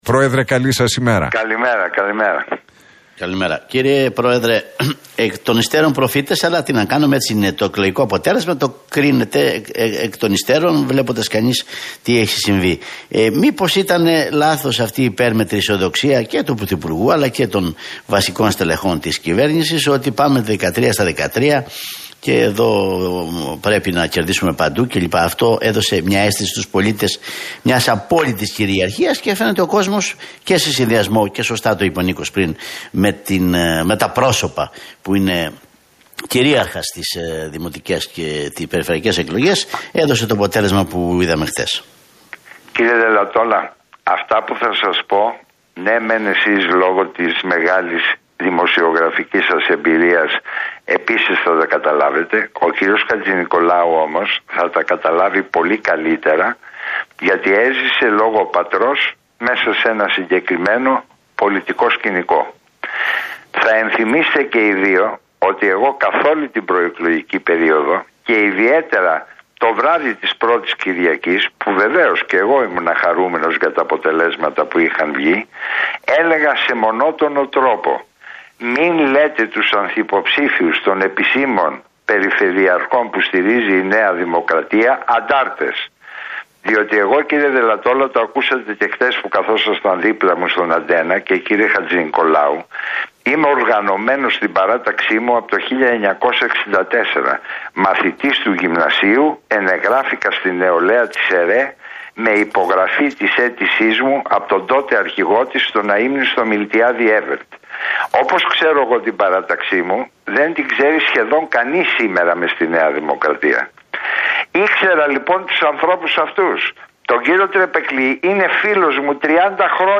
Ο Νικήτας Κακλαμάνης μίλησε στην εκπομπή